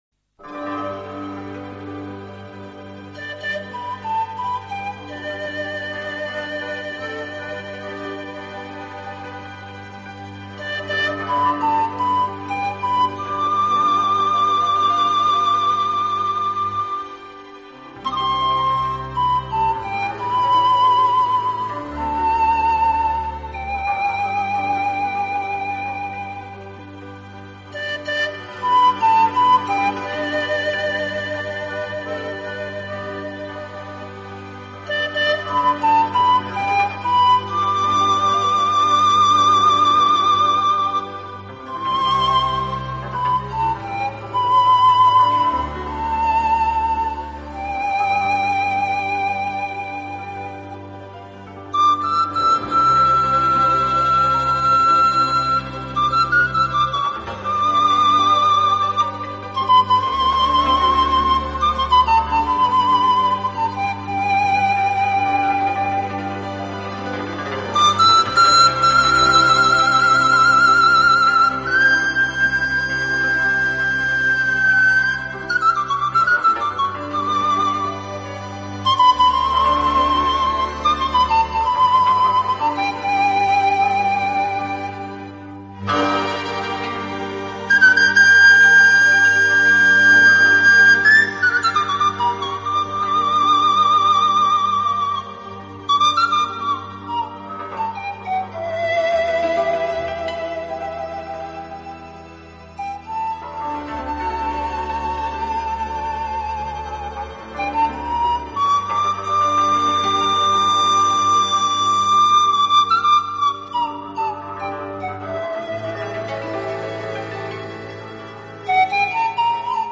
【精品排箫】